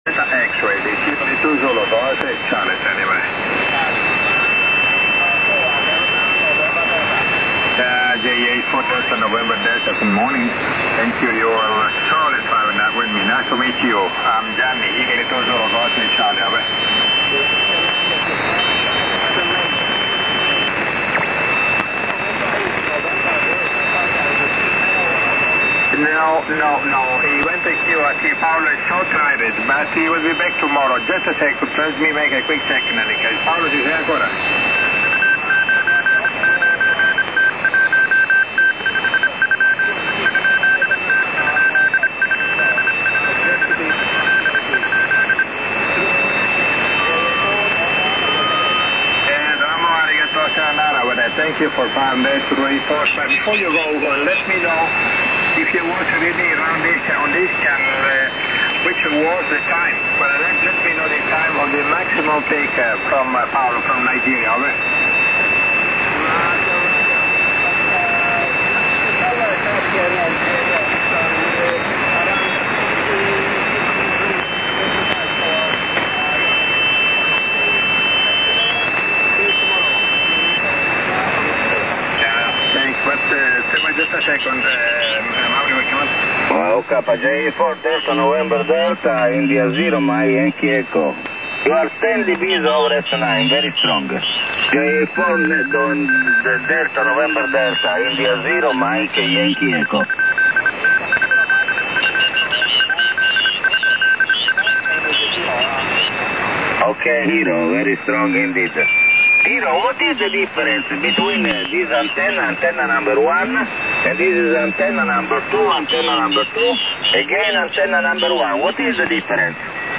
JUMA-RX1 live recordings